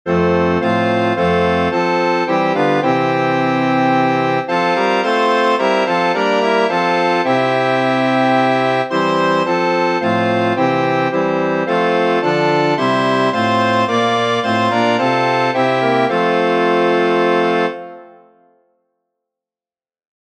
Órgano